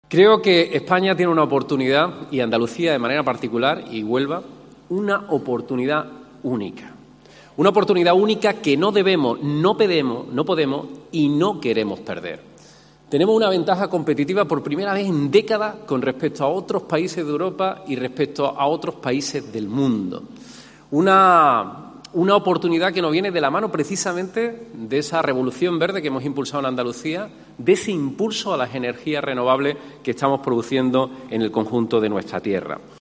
El presidente de la Junta de Andalucía, Juanma Moreno, ha sido uno de los encargados de inaugurar la cita más importante del sector y que se celebra en la capital onubense